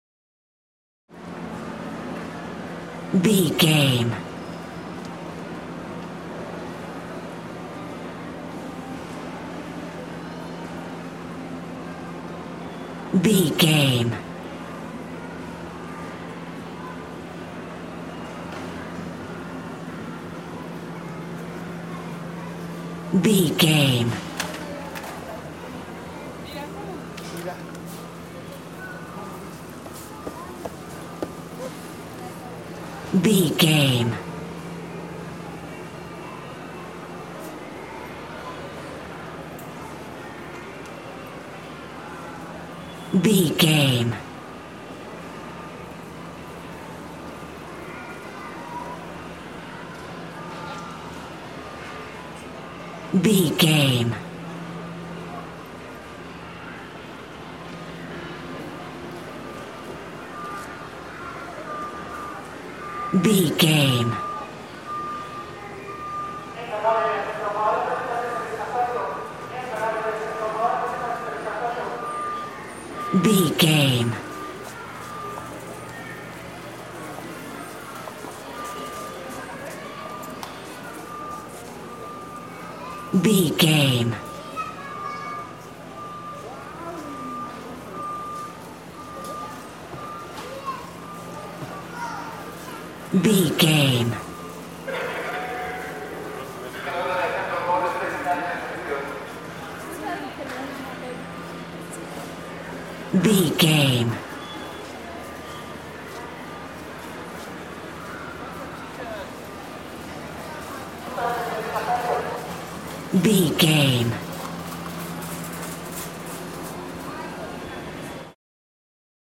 Supermarket ambience wallas
Sound Effects
urban
ambience